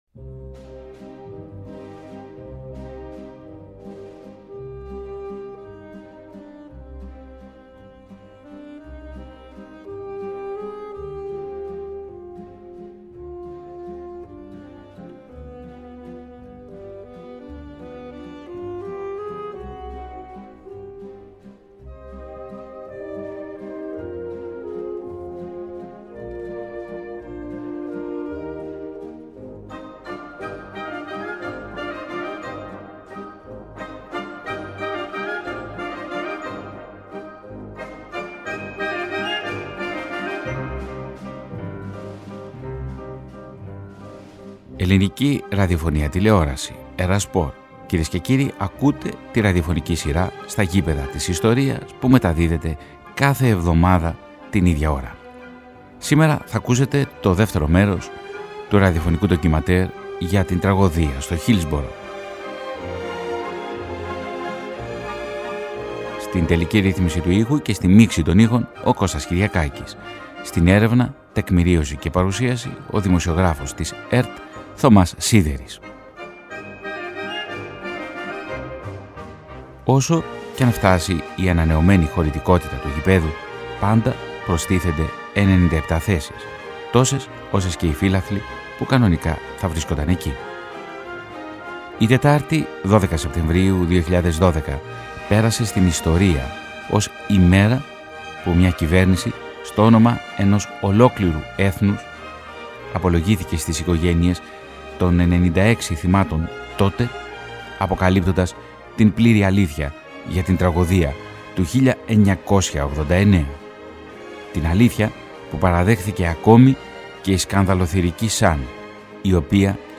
Το ραδιοφωνικό ντοκιμαντέρ ανέδειξε τον αγώνα των συγγενών των θυμάτων, που για περισσότερες από τρεις δεκαετίες πάλεψαν για την αναγνώριση της αλήθειας και την αποκατάσταση της μνήμης των ανθρώπων τους.